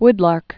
(wdlärk) also wood lark